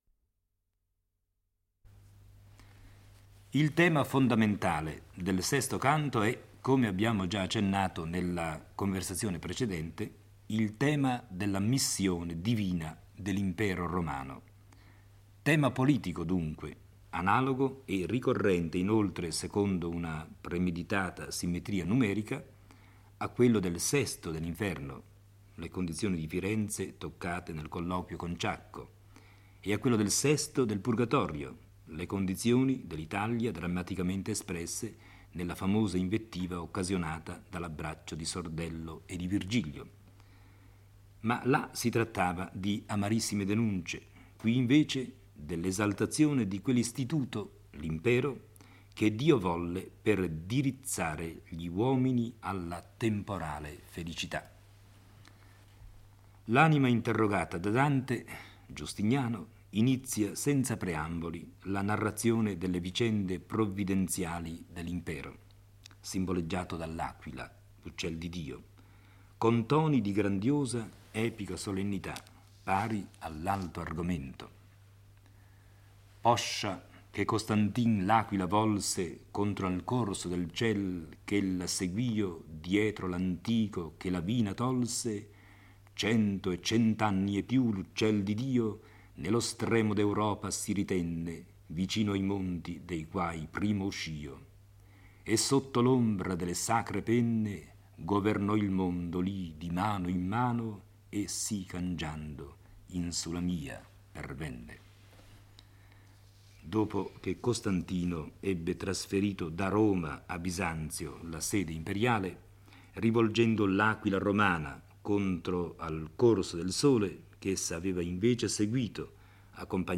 legge e commenta il VI canto del Paradiso. Siamo nel II Cielo di Mercurio, dove risiedono le anime di coloro che si attivarono per conseguire fama e onori terreni. Lo spirito di Giustiniano dichiara la propria identità dicendo che, dopo il trasferimento dell'aquila imperiale da Roma a Bisanzio, essa finì nelle sue mani.